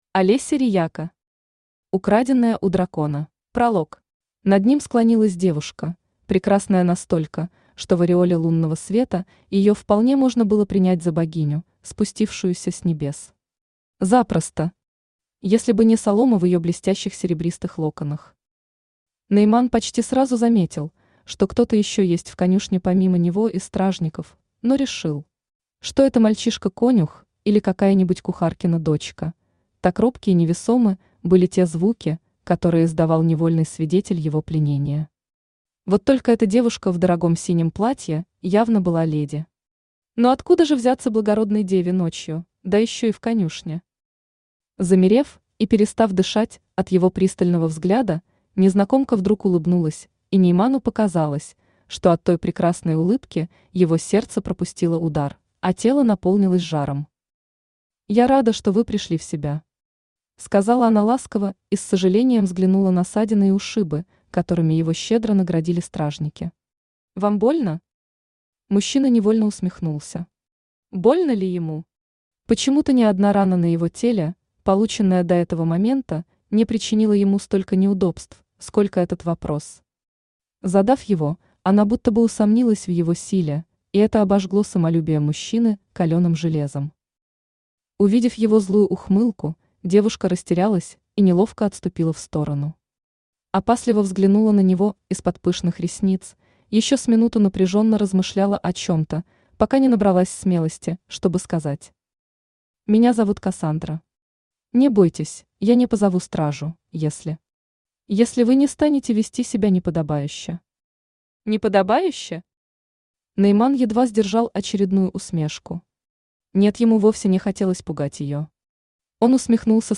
Aудиокнига Украденная у дракона Автор Олеся Рияко Читает аудиокнигу Авточтец ЛитРес.